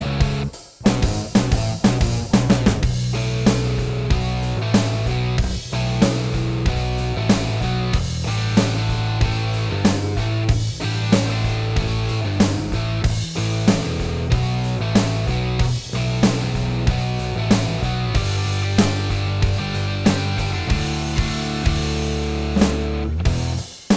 Minus Lead And Solo Guitars Rock 4:09 Buy £1.50